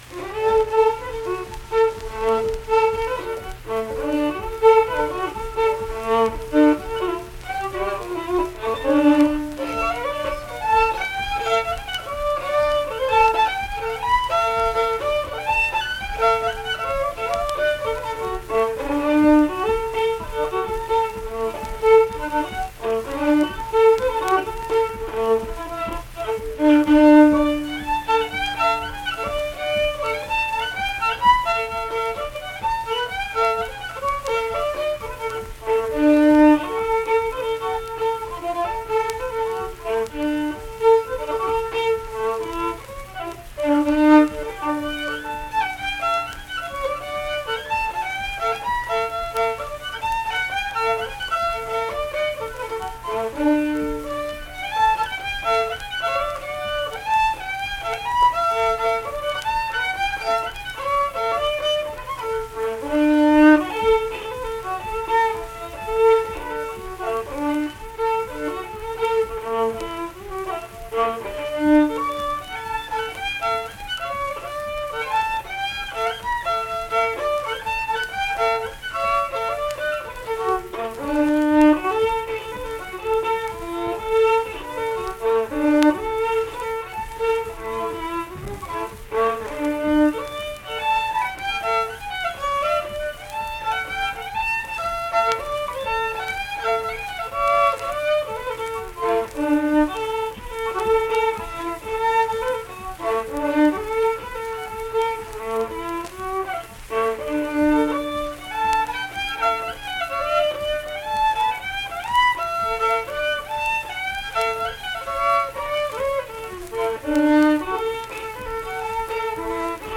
Unaccompanied fiddle performance
Instrumental Music
Fiddle